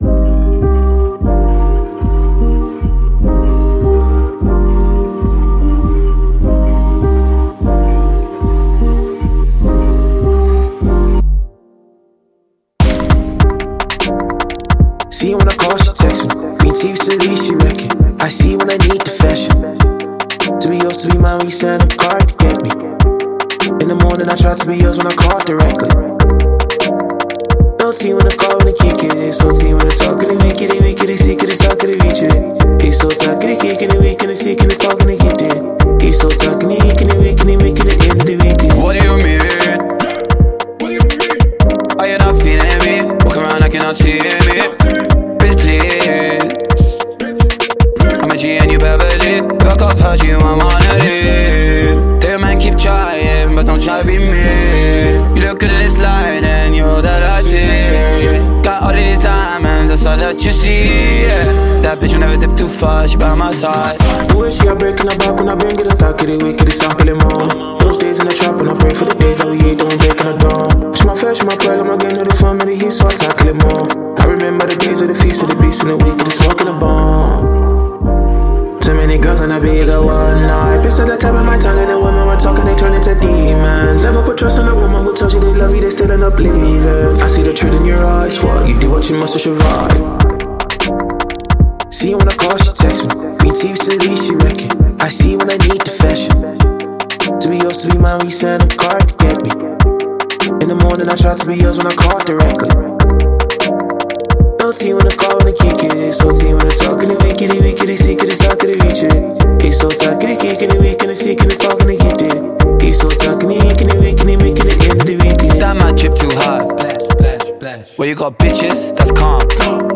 Alté singer